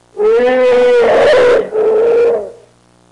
Elephant Sound Effect
Download a high-quality elephant sound effect.
elephant-1.mp3